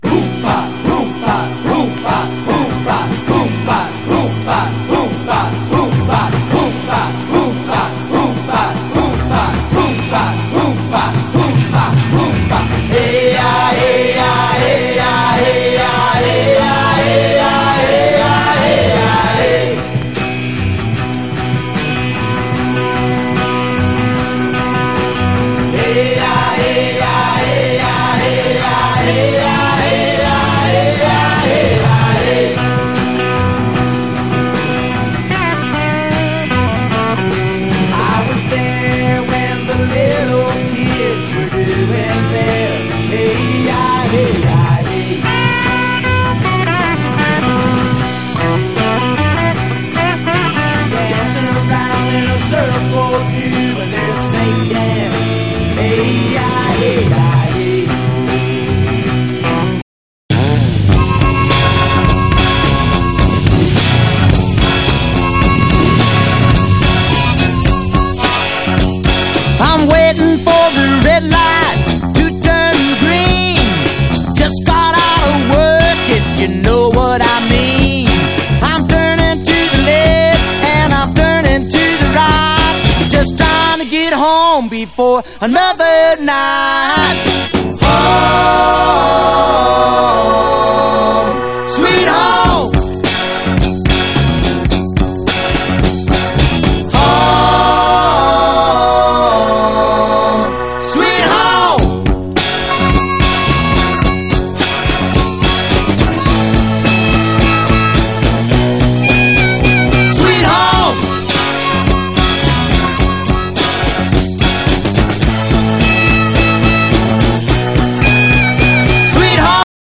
辺境トリップ感あふれるサイケ〜ディスコ〜フォークを横断した変わり種コンピレーション！